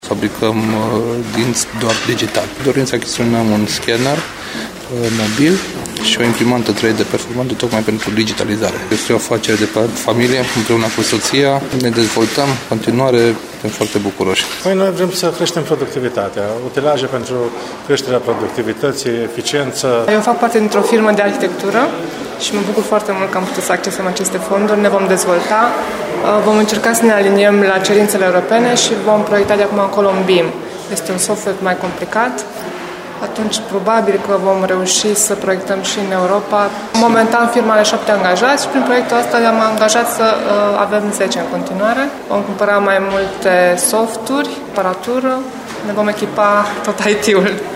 voxuri-semnare-contracte.mp3